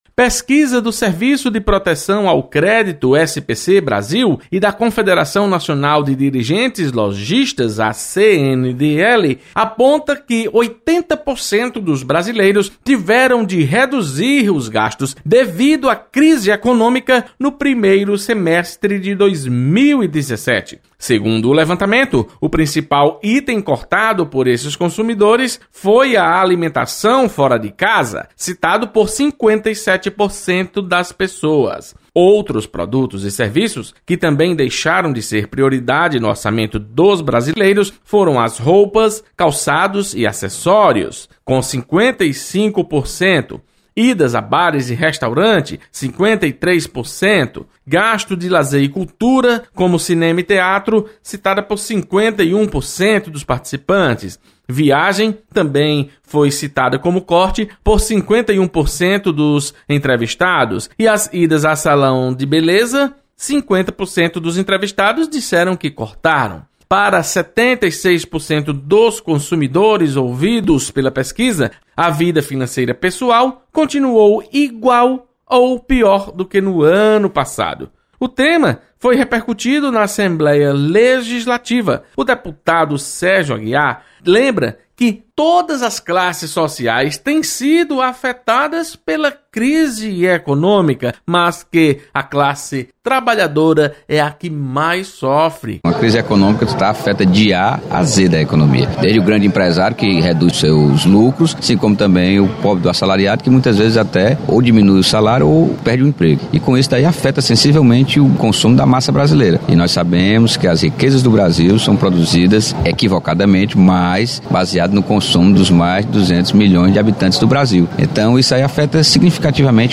Deputados comentam pesquisa que aponta corte dos gastos pelos brasileiros.